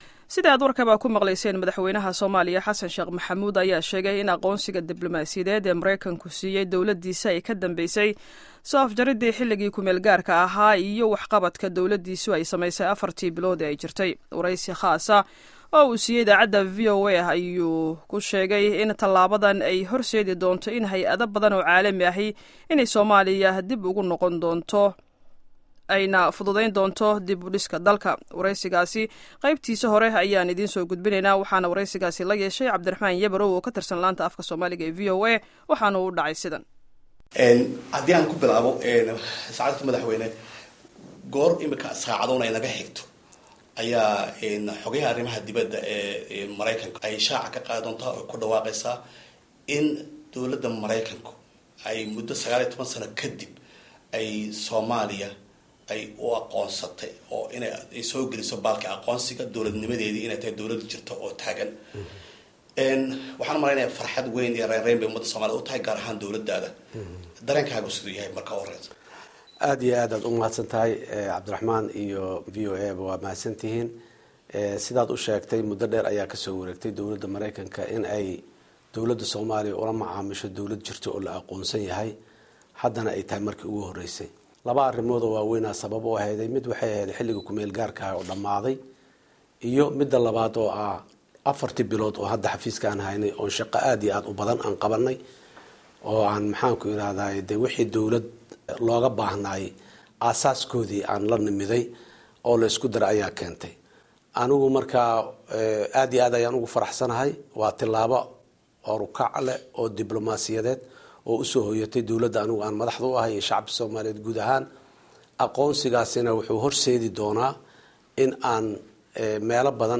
Wareysigii Madaxweyne Xasan Sheekh